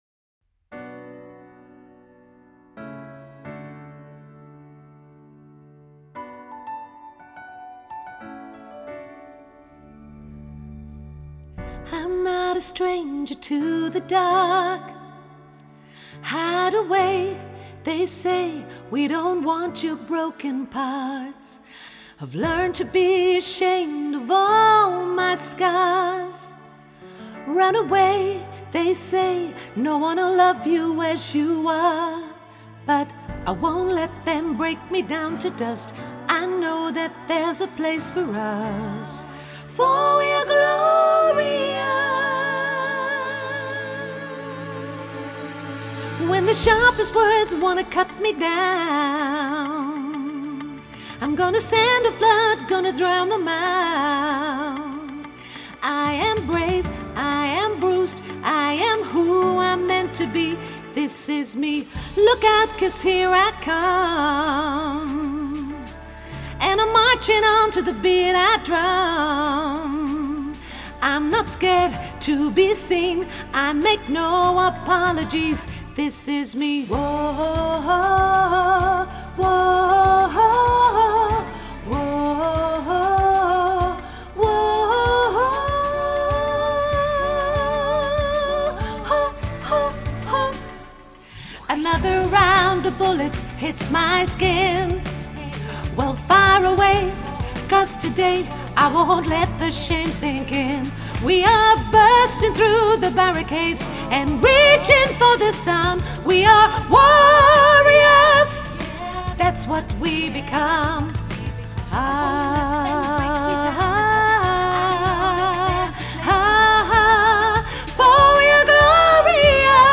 hoog sopraan